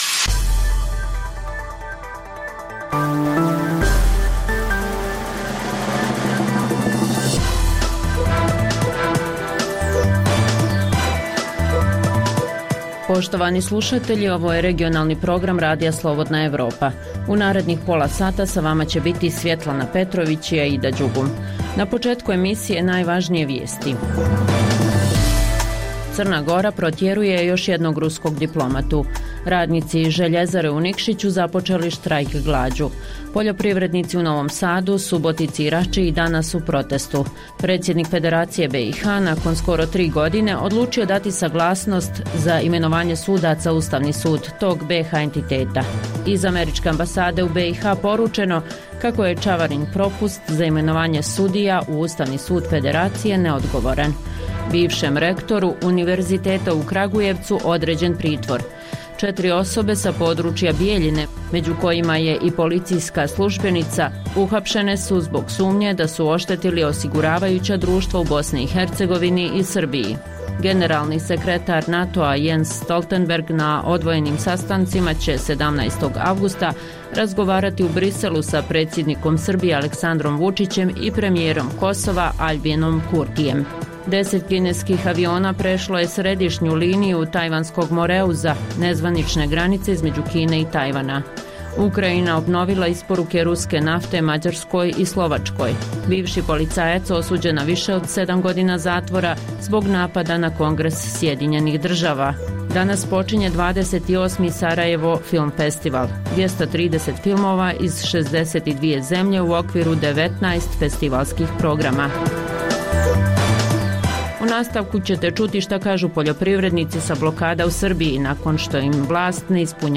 Reportaže iz svakodnevnog života ljudi su svakodnevno takođe sastavni dio “Dokumenata dana”.